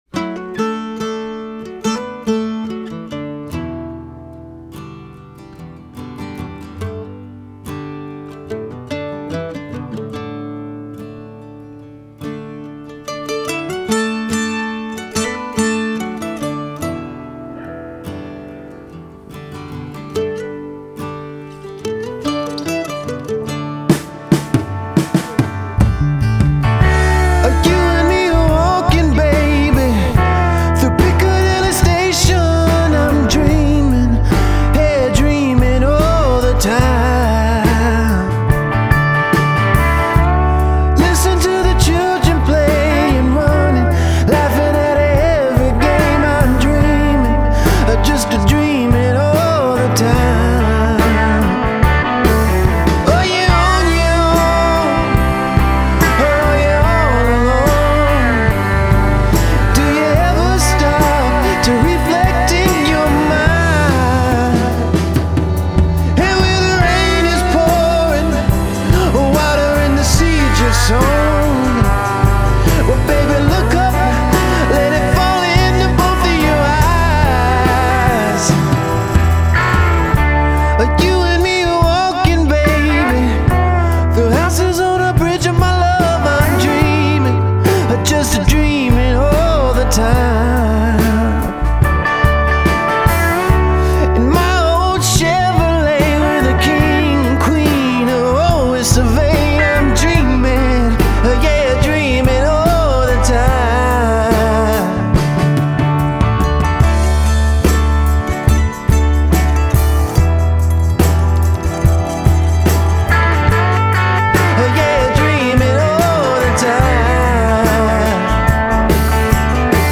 which features a stellar melody and some great guitar work